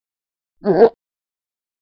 漱口水
描述：密切记录的碳酸水。每次漱口后都会吞下液体。
标签： 人的声音 漱口 口腔
声道立体声